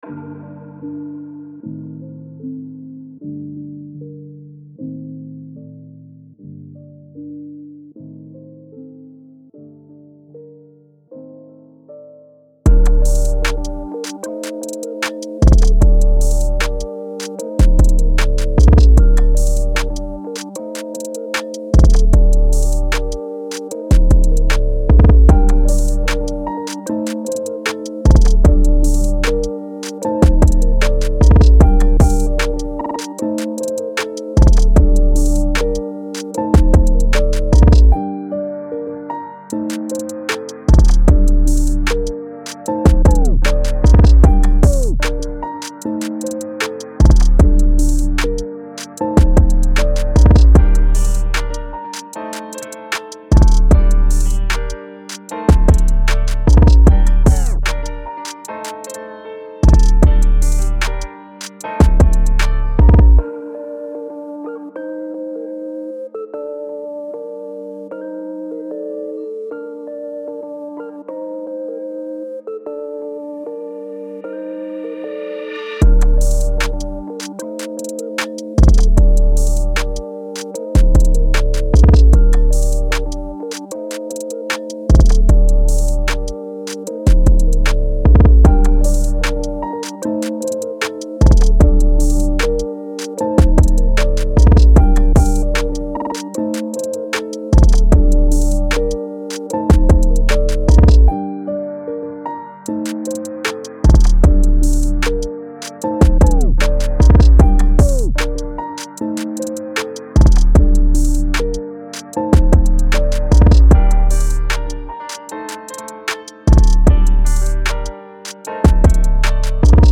Hip-Hop , Trap